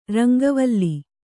♪ rangavalli